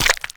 Minecraft Version Minecraft Version snapshot Latest Release | Latest Snapshot snapshot / assets / minecraft / sounds / block / frogspawn / break3.ogg Compare With Compare With Latest Release | Latest Snapshot
break3.ogg